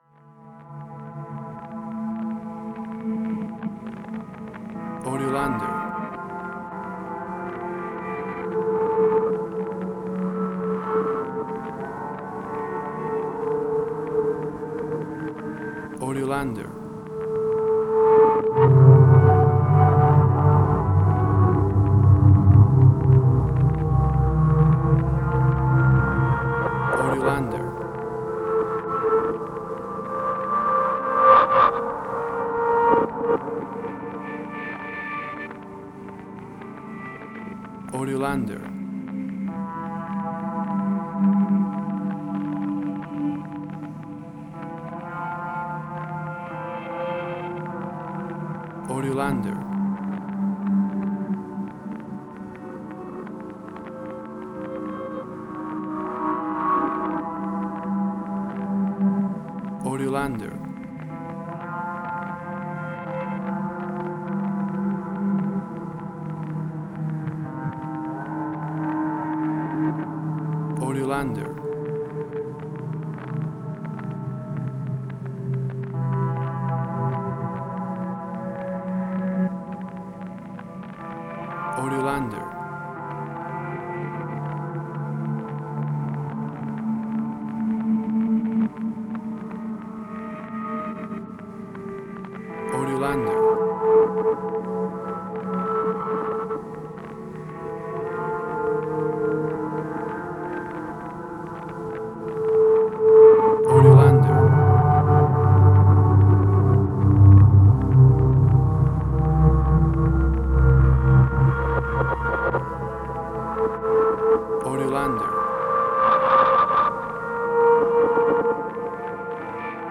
World Ambient, Similar City of Ghosts Supernatural.